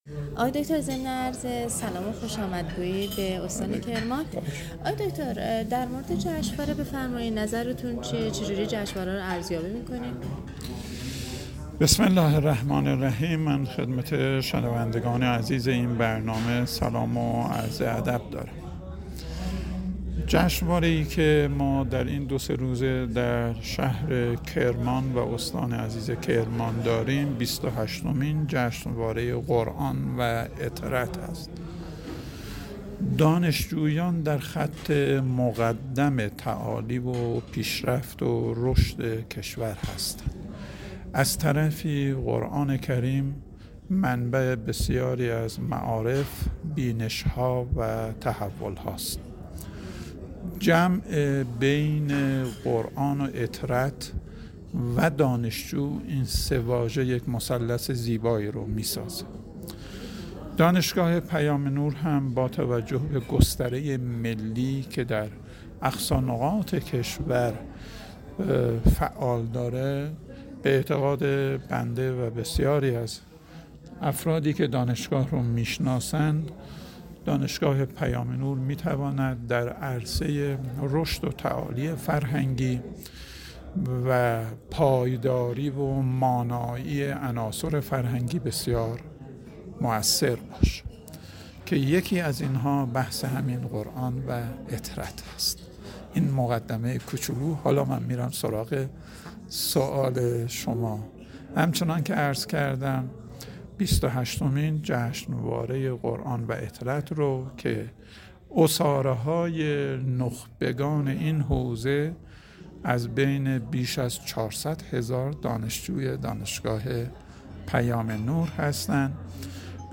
مراسم افتتاحیه بیست و هشتمین جشنواره قرآن و عترت دانشگاه پیام نور به میزبانی کرمان با حضور ۱۲۰ برگزیده در رشته‌های مختلف از ۳۱ استان در گلزار شهدای کرمان برگزار شد.